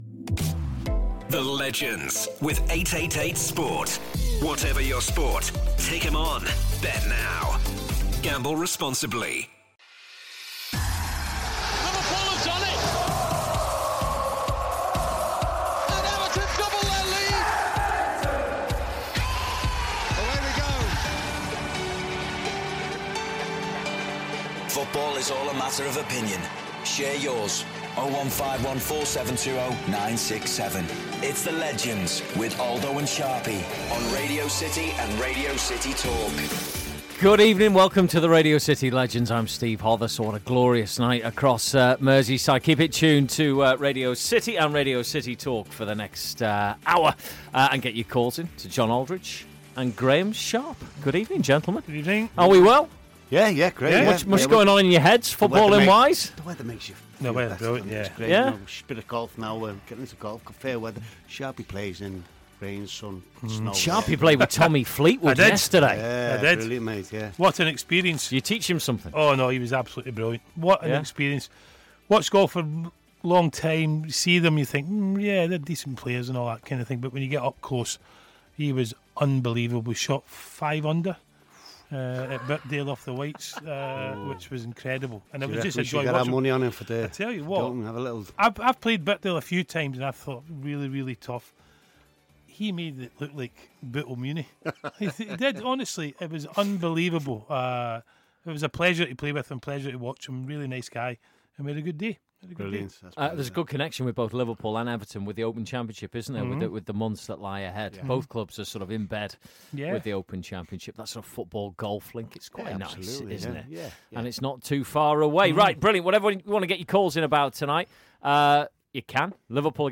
There's also a special guest as Tranmere Chairman Mark Palios joins the lads to talk about Rovers' playoff hopes.